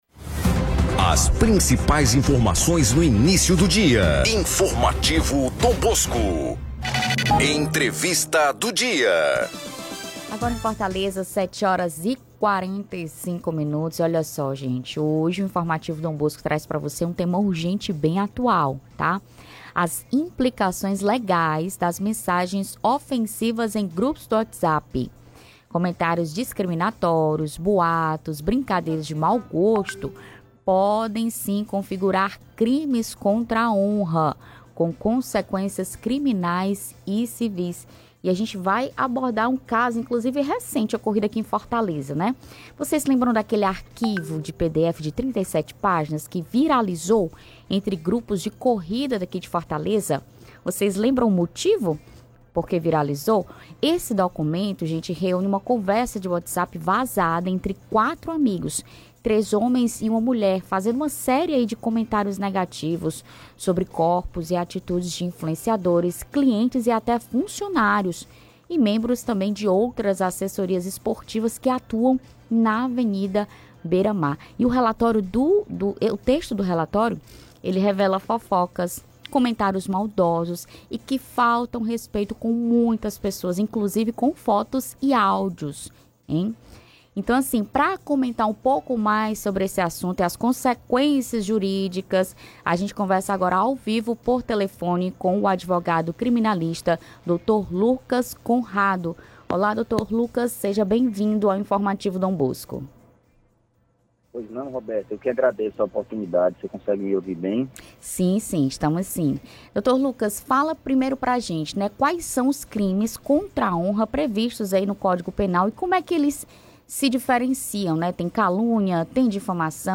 Mensagens que ferem a honra: as implicações legais da difamação no WhatsApp; confira entrevista